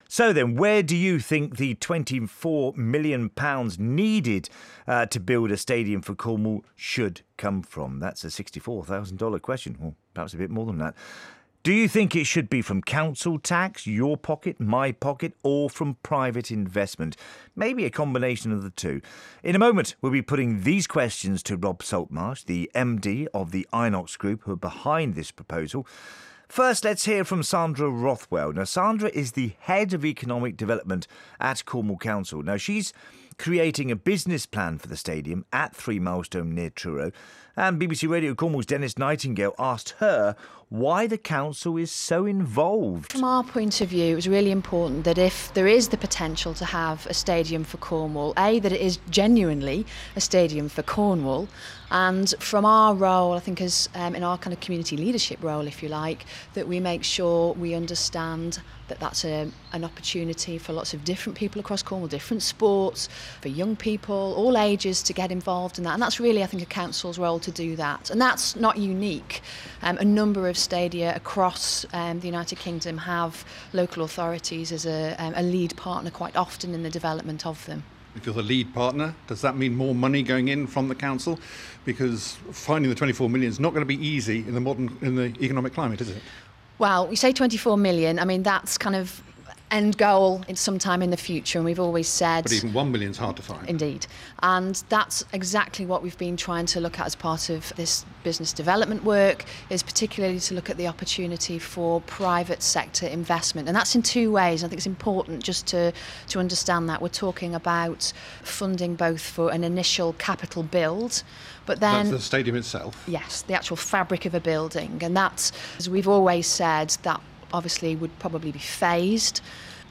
Meanwhile here is a shorter interview, broadcast on the same day